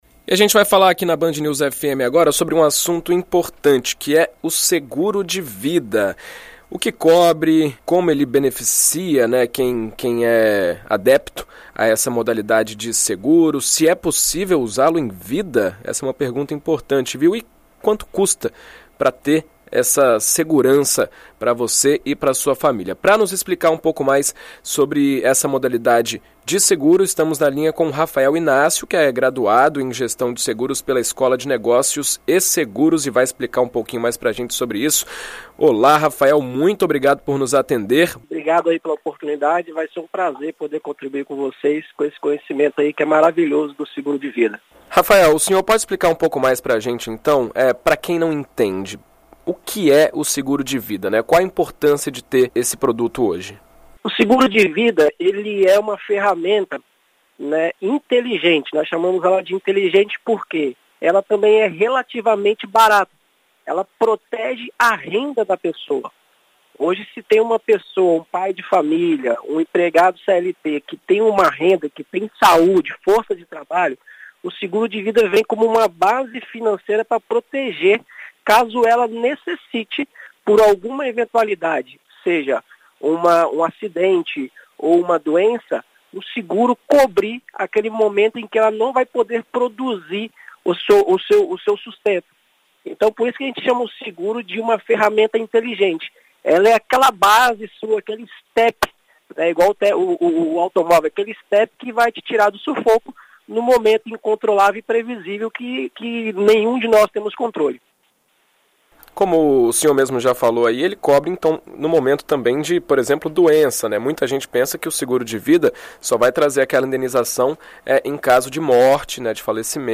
Em entrevista à rádio BandNews FM ES